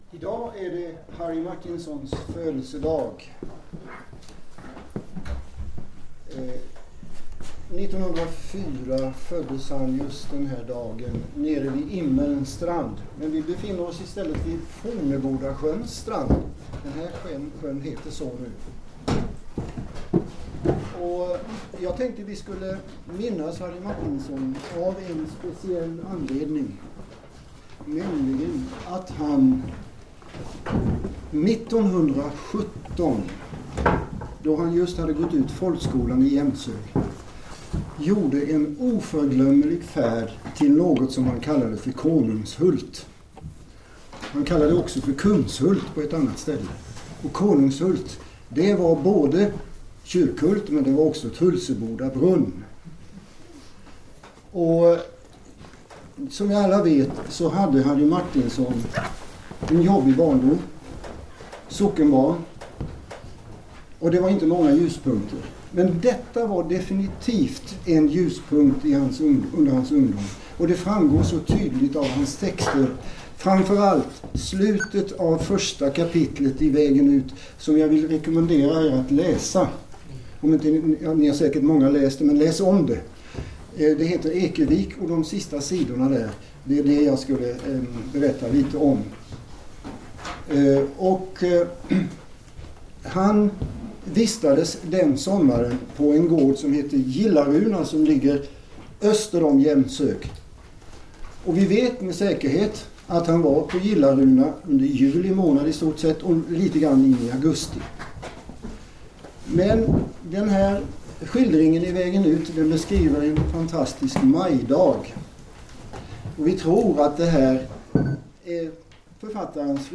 Inspelning den 6 maj 2016, Fornebodastugan, Kyrkhult, Blekinge.